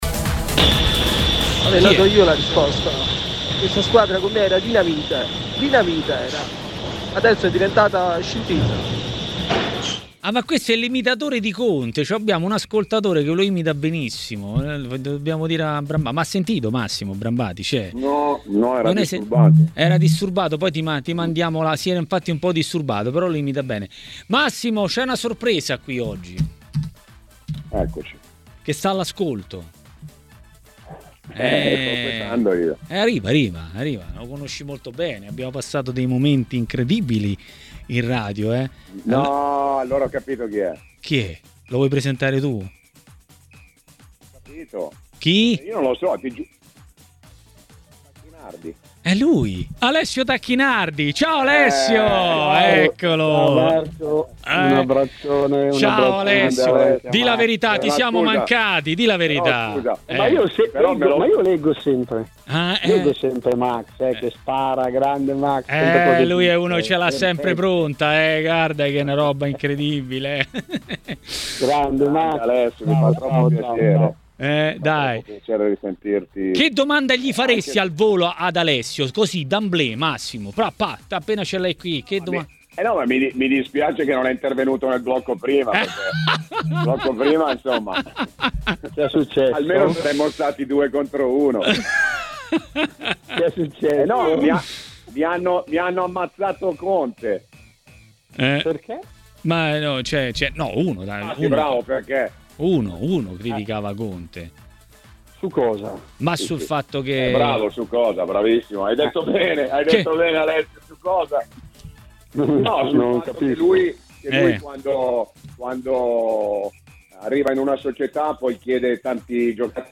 A commentare il campionato e i suoi temi a Maracanà, nel pomeriggio di TMW Radio, è stato l'ex calciatore e tecnico Alessio Tacchinardi.